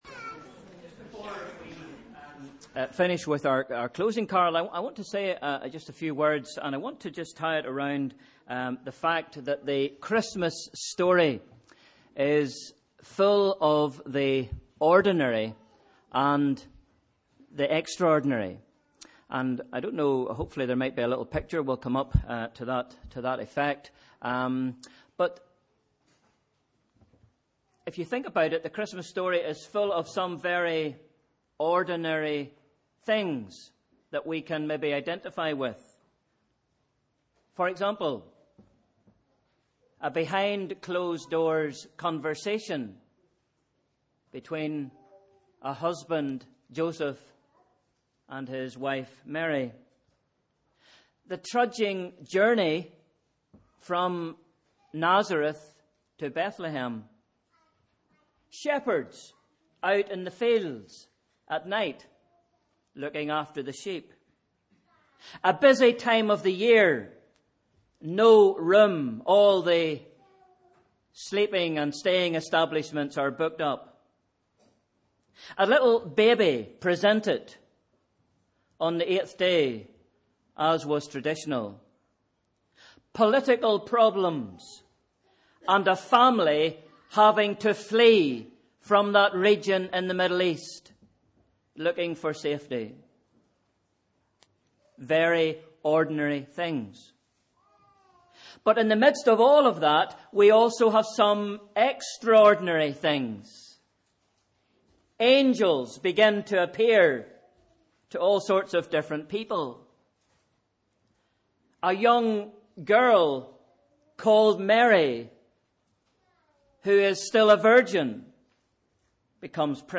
Sunday 13th December – Evening Service